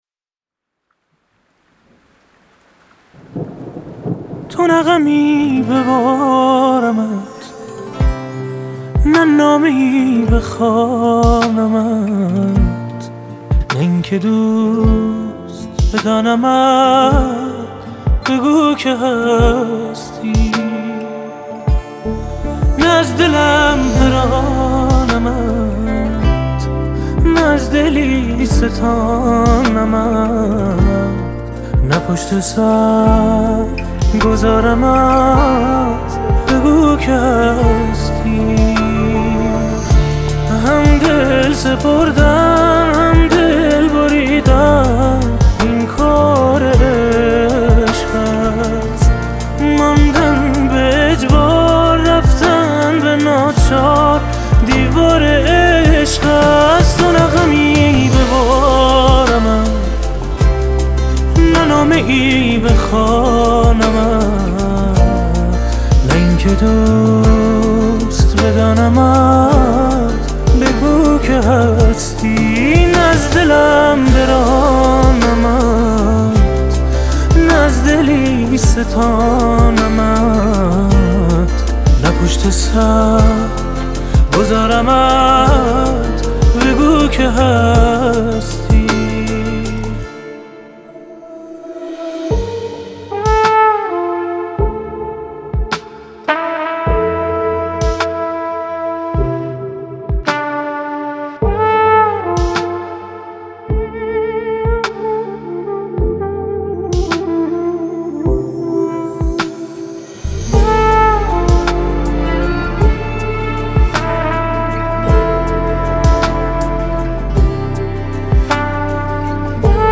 خواننده موسیقی پاپ
با حال و هوایی عاشقانه